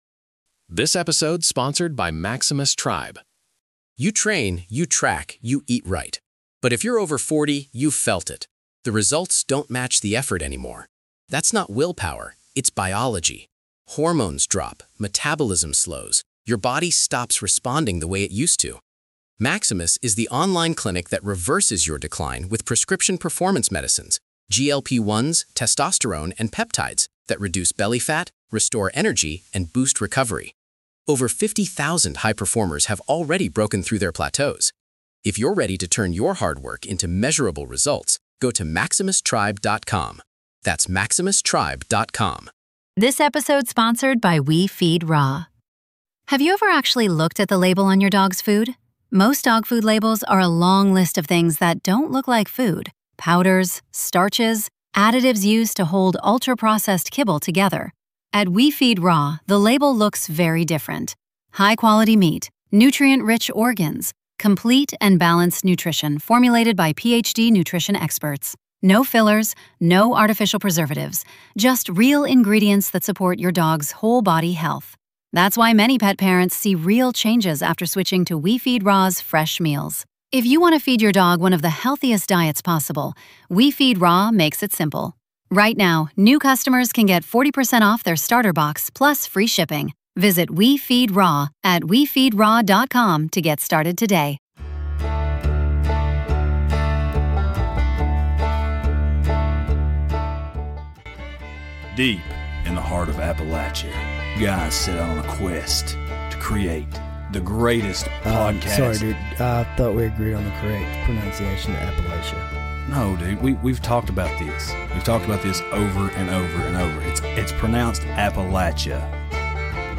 The guys chat it up about CERN located right outside Geneva, Switzerland. CERN is a an organization of scientists that delve into particle and Quantum physics in an attempt to explore and define the universe and how it was made.